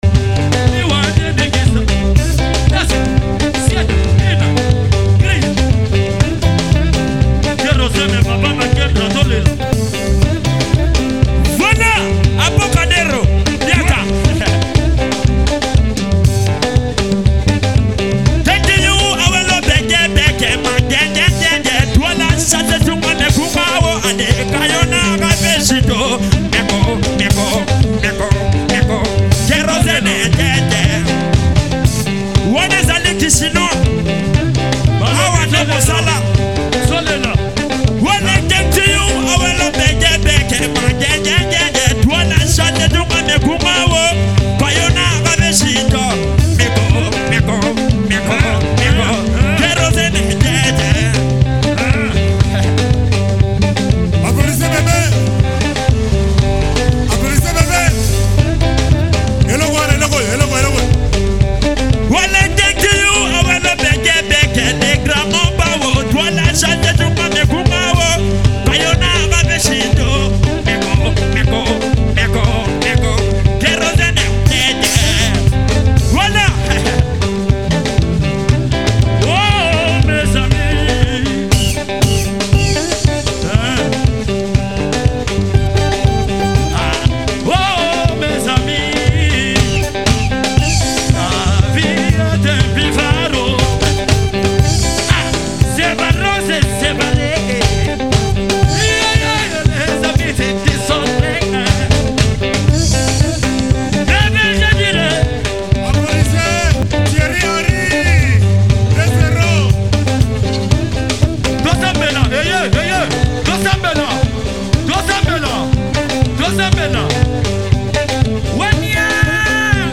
complex arrangement
soaring vocal performance